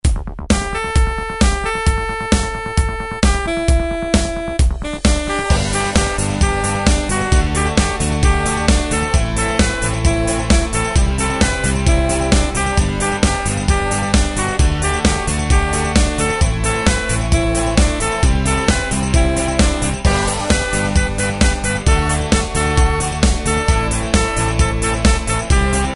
Tempo: 132 BPM.
MP3 with melody DEMO 30s (0.5 MB)zdarma